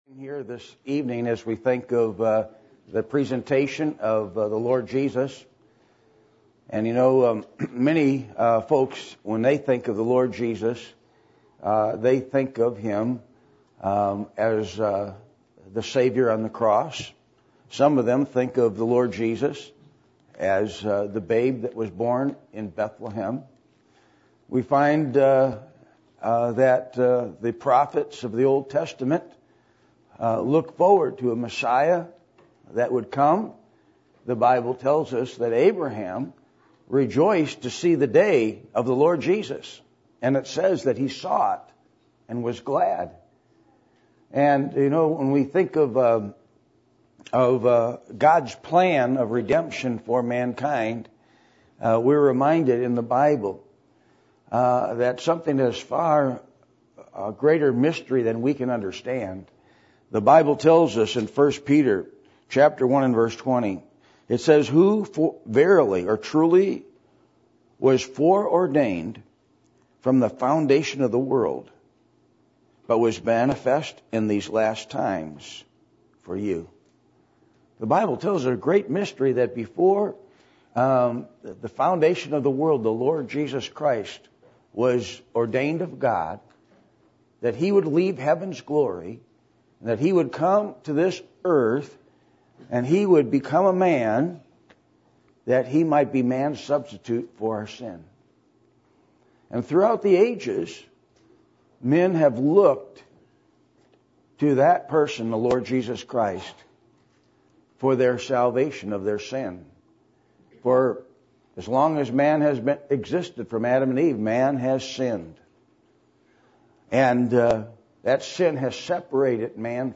Christmas Program